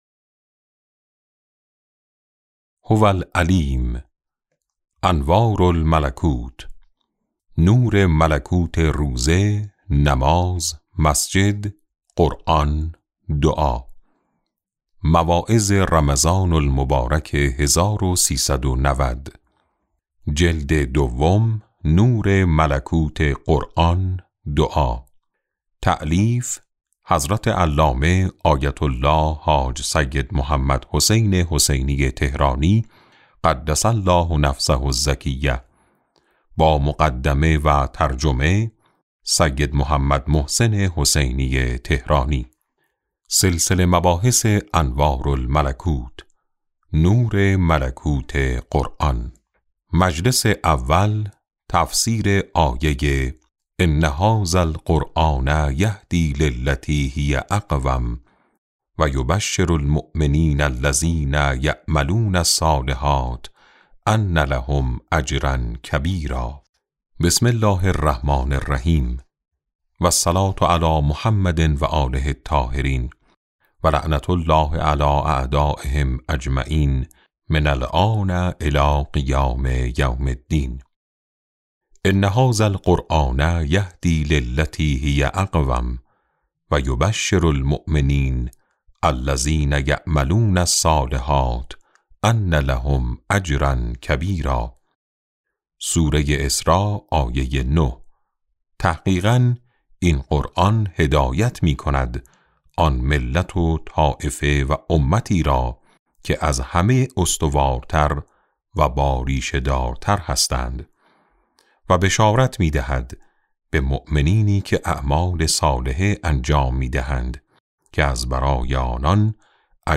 کتاب صوتی انوار الملکوت ج2 - جلسه2